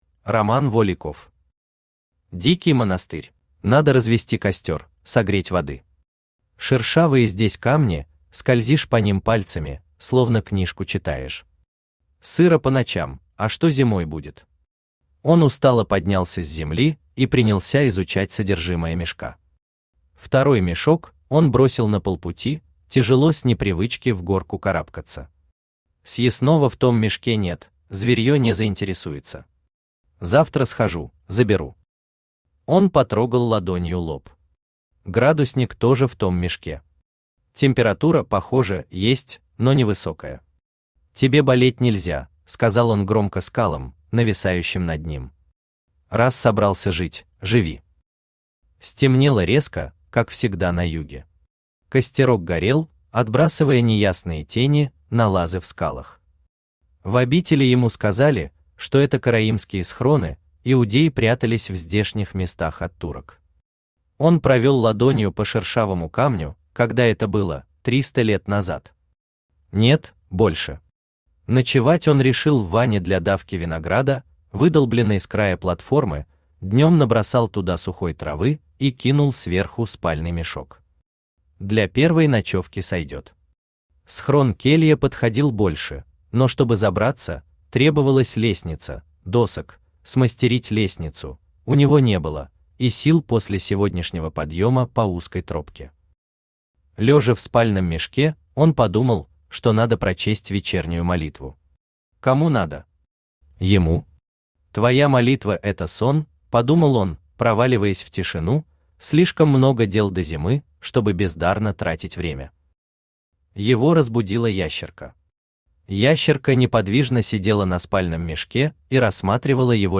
Аудиокнига Дикий монастырь | Библиотека аудиокниг
Aудиокнига Дикий монастырь Автор Роман Воликов Читает аудиокнигу Авточтец ЛитРес.